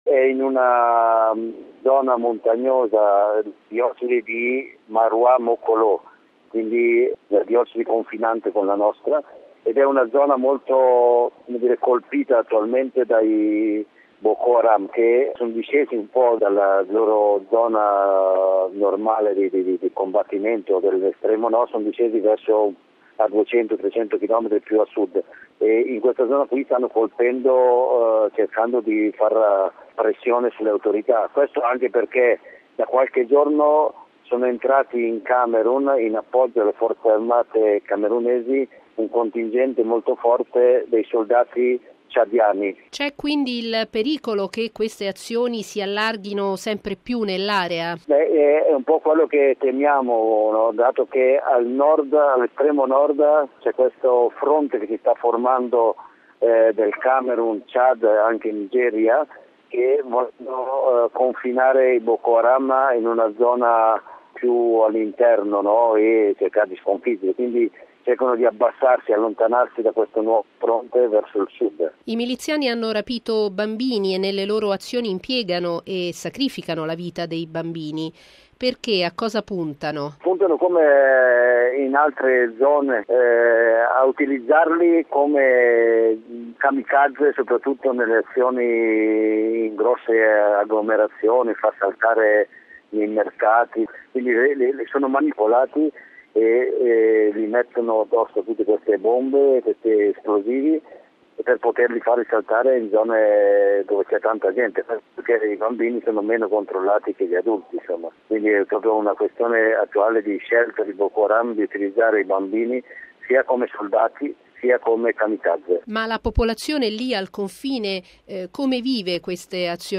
ha raggiunto telefonicamente